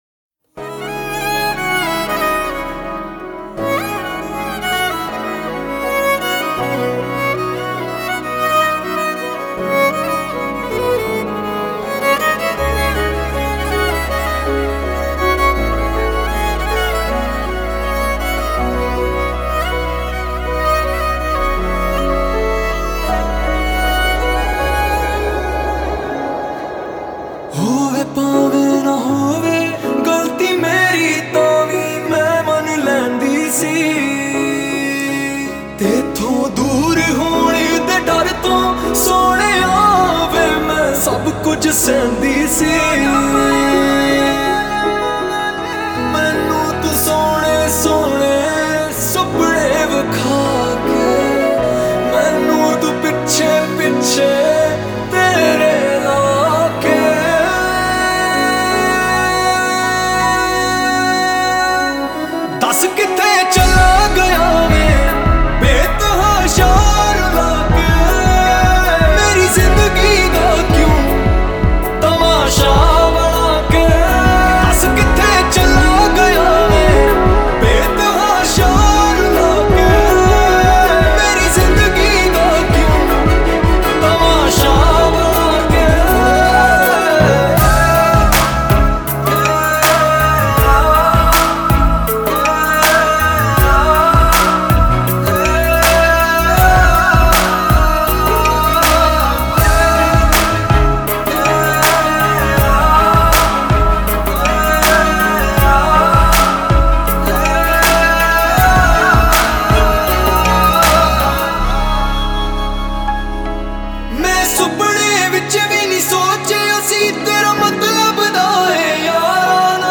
2020 Pop Mp3 Songs
Punjabi Bhangra MP3 Songs